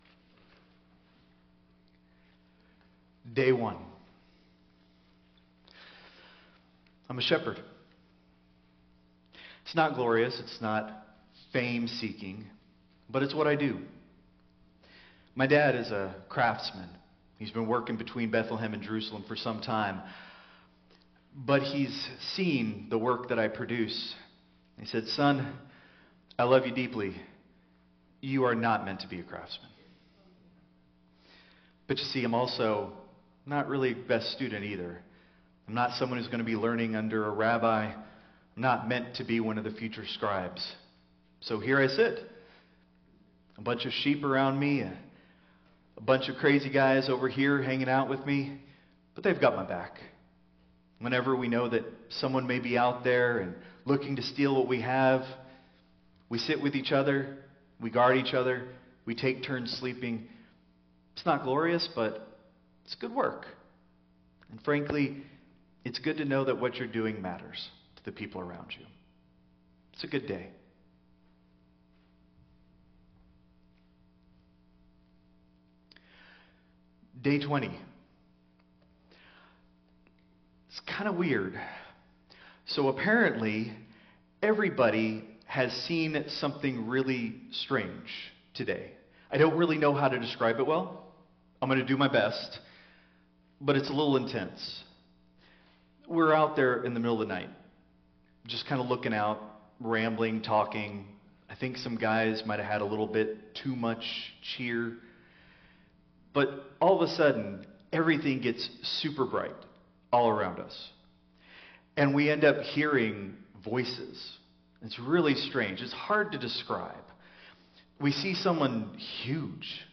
Christ Memorial Lutheran Church - Houston TX - CMLC 2024-12-24 Sermon (Midnight)